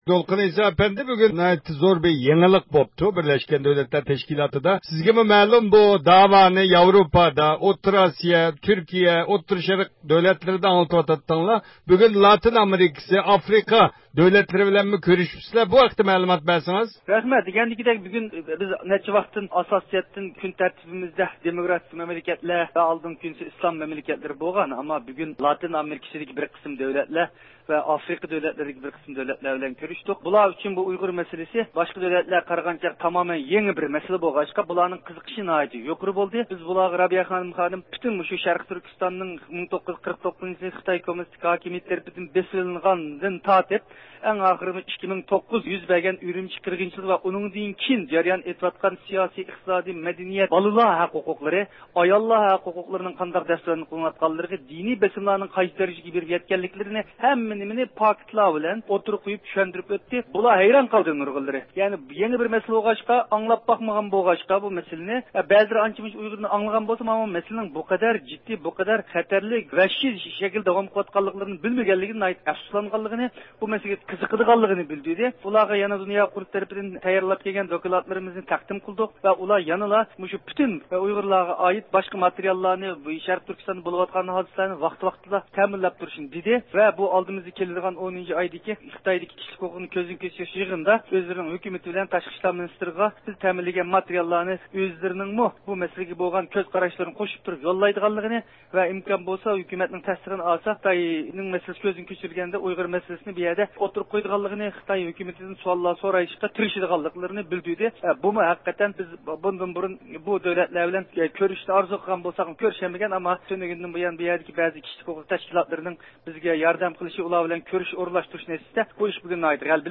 بىز نەق مەيدانغا تېلېفون قىلىپ د ئۇ ق ئىجرائىيە كومىتېتى مۇدىرى دولقۇن ئەيسا ئەپەندىدىن تەپسىلىي مەلۇمات ئىگىلىدۇق.